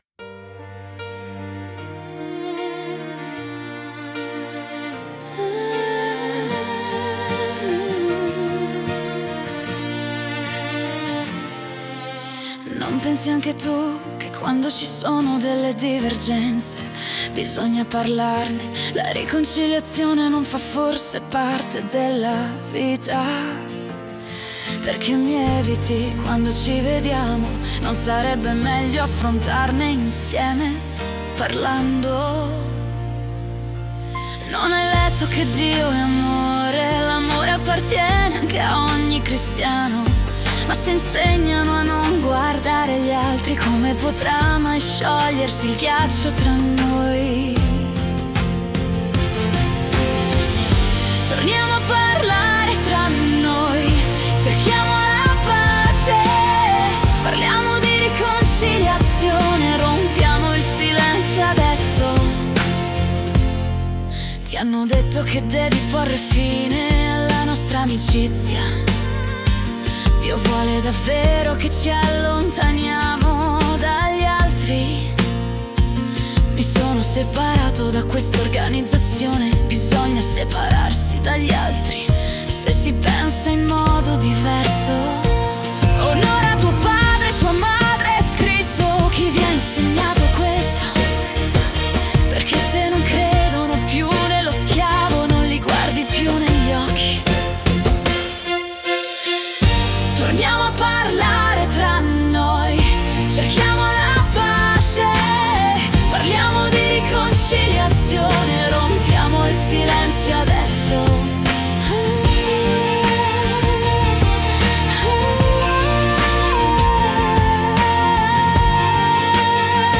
Canzoni